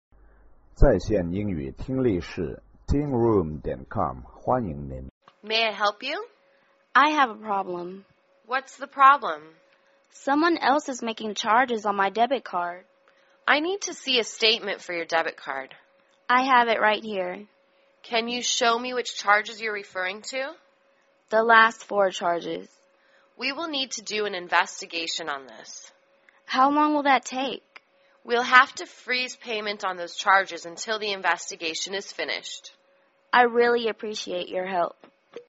银行英语对话-Reporting Charges(3) 听力文件下载—在线英语听力室